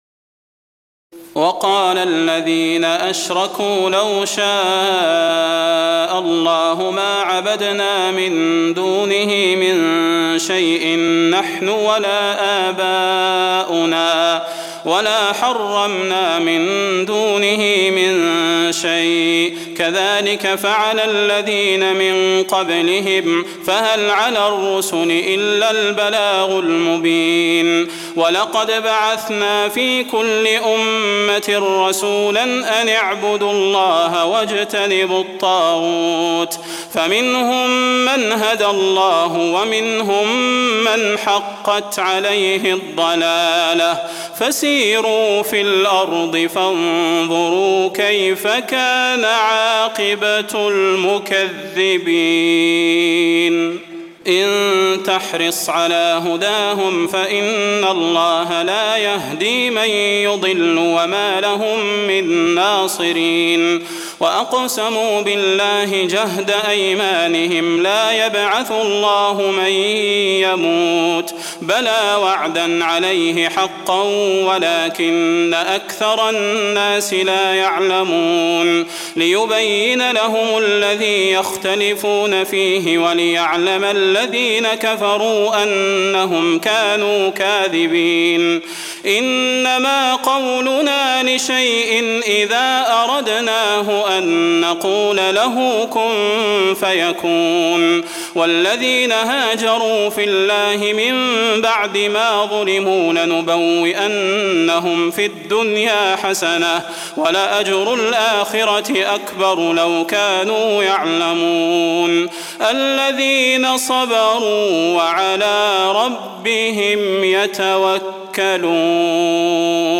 تراويح الليلة الثالثة عشر رمضان 1423هـ من سورة النحل (35-89) Taraweeh 13 st night Ramadan 1423H from Surah An-Nahl > تراويح الحرم النبوي عام 1423 🕌 > التراويح - تلاوات الحرمين